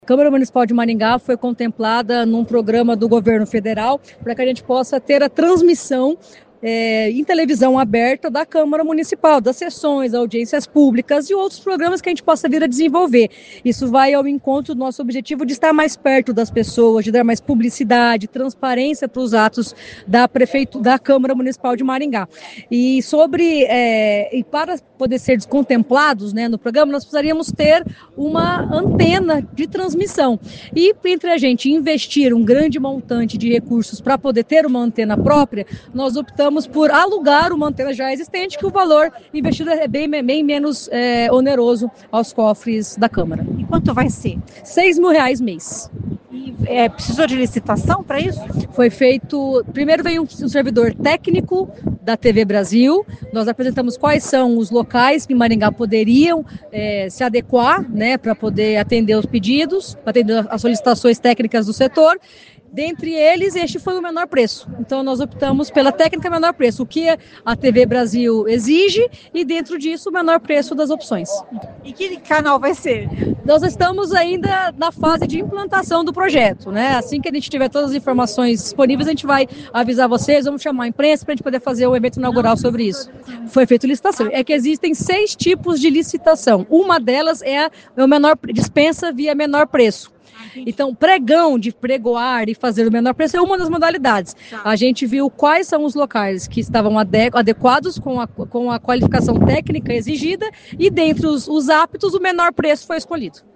Ouça o que diz a presidente da Câmara de Maringá, Majô Capdeboscq.